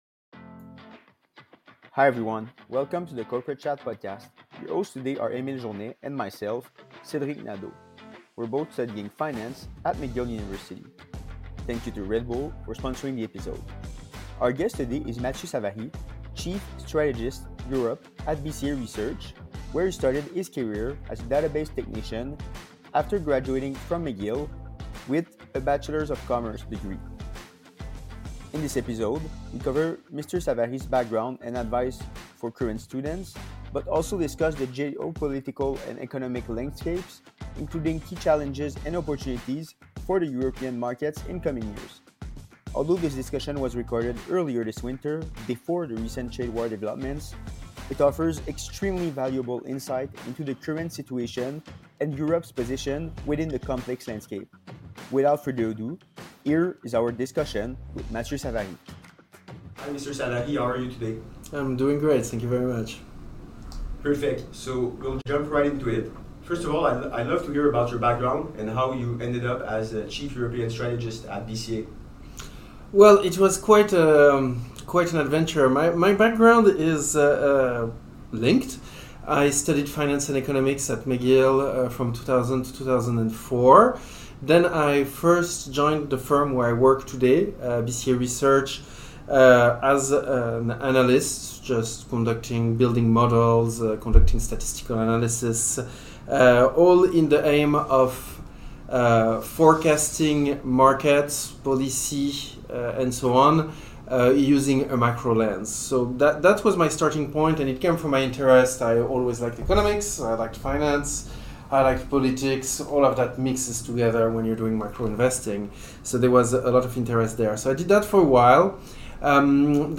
Our podcast is comprised of university students driven to create a pathway between the leaders of the industry, and the minds of the future in business. Our podcast will have a focus on finance, however, throughout the episodes we will dive into other aspects of the business world, i.e. tech and quantitative finance.